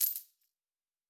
Coins 09.wav